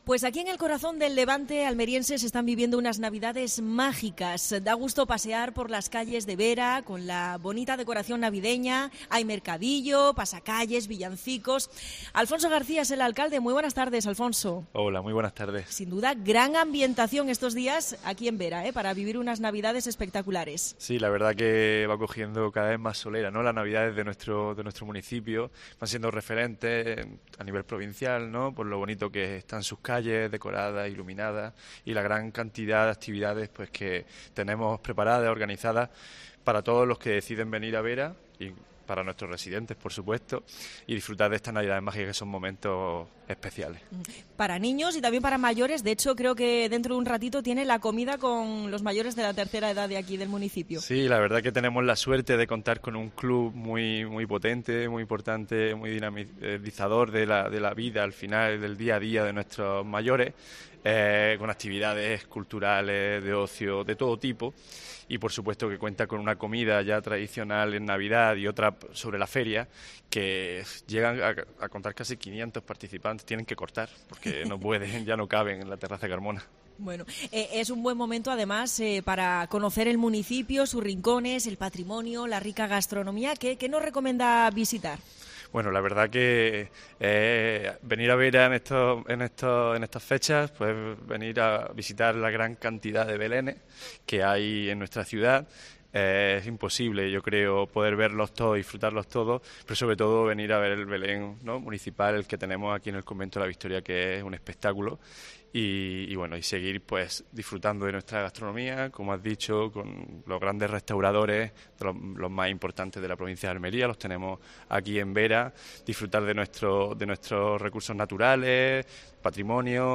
entrevista a Alfonso García (alcalde de Vera).